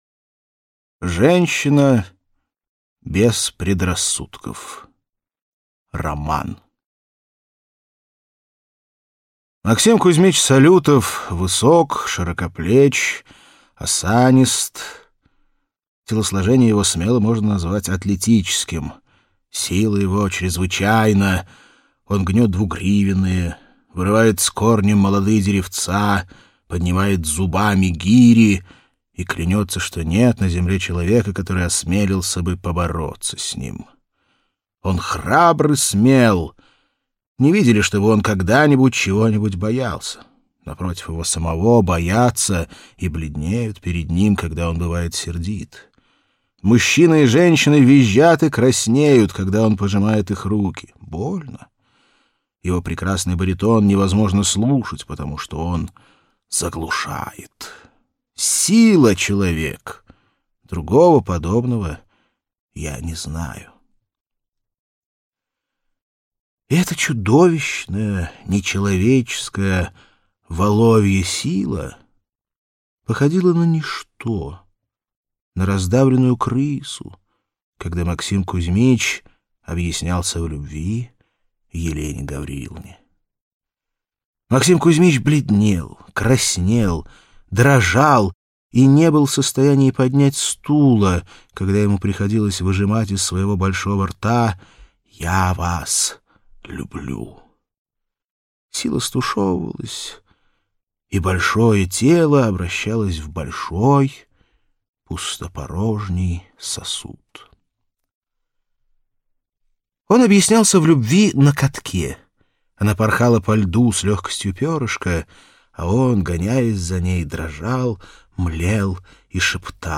Аудиокнига Короткие юмористические рассказы Антоши Чехонте | Библиотека аудиокниг